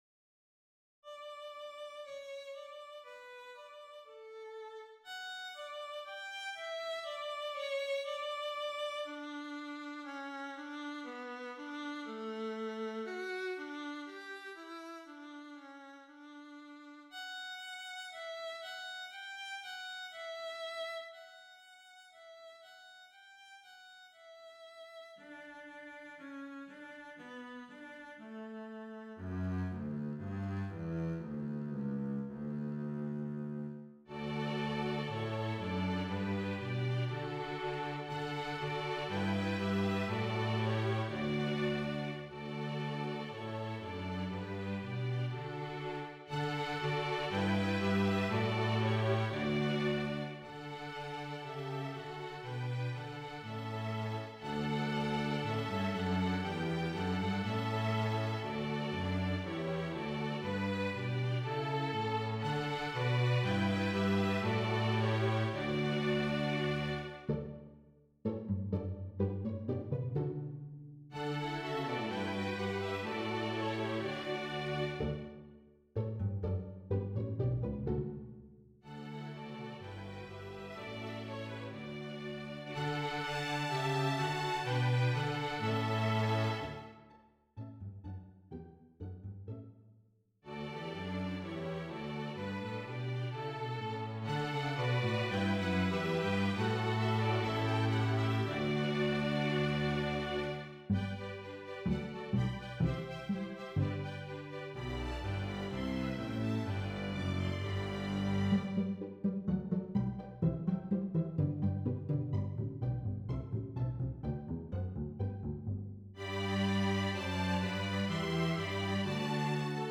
Three Sacred Songs for String Orchestra
This version is a medley instead of a suite with a transition between each song.
HYMN MUSIC